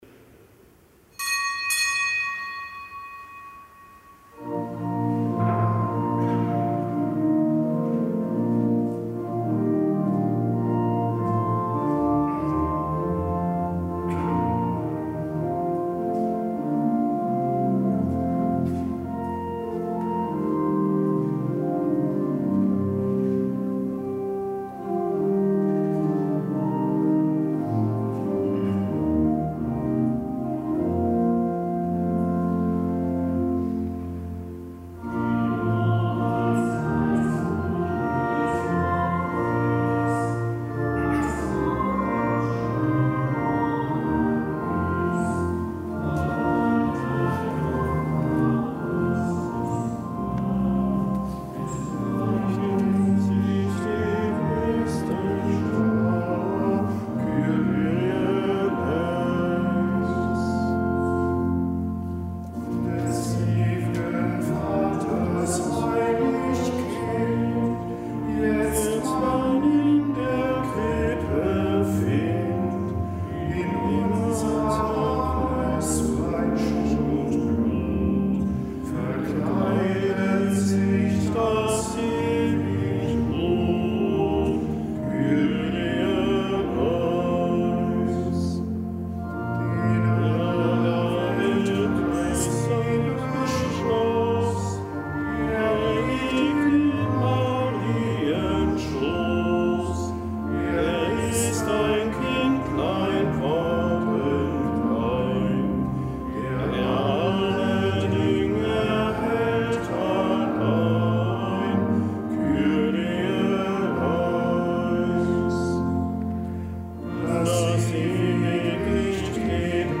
Kapitelsmesse am Samstag der Weihnachtszeit
Kapitelsmesse aus dem Kölner Dom am Samstag der Weihnachtszeit.